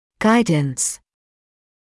[‘gaɪdns][‘гайднс]управление; наведение; помощь (в осуществлении процесса)